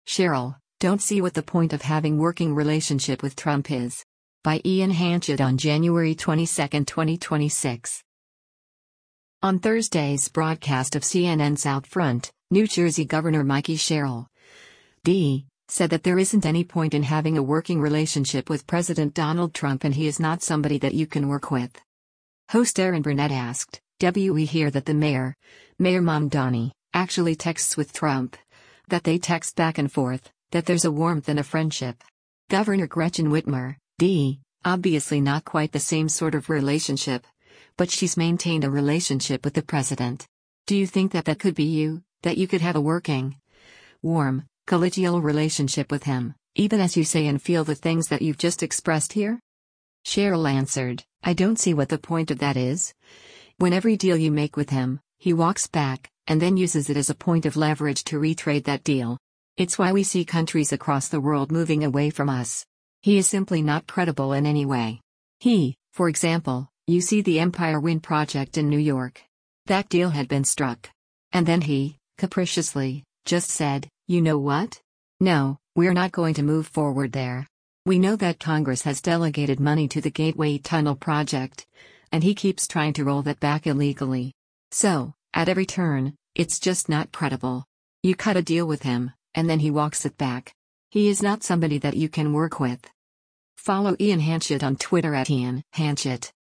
On Thursday’s broadcast of CNN’s “OutFront,” New Jersey Gov. Mikie Sherrill (D) said that there isn’t any point in having a working relationship with President Donald Trump and “He is not somebody that you can work with.”